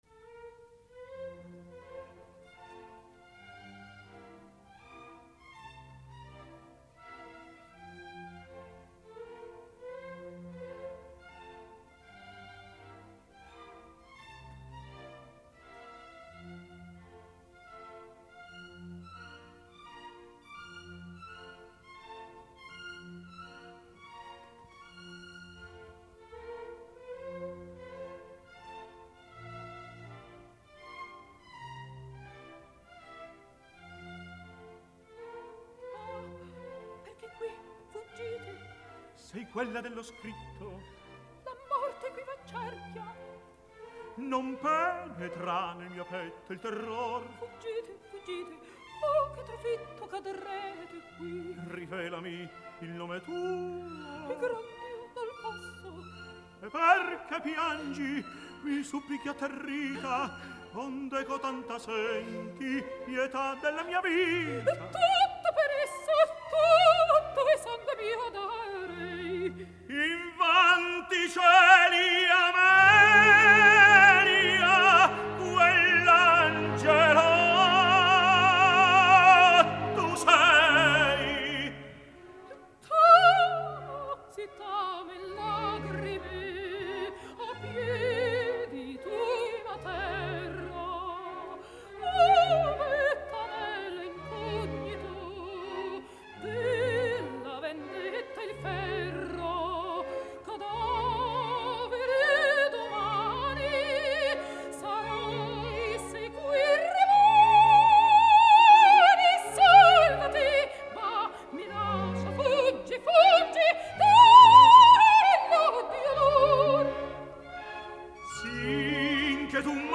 Сегодня день рождения знаменитого итальянского тенора Джузеппе ди Стефано (1921-2008) !!!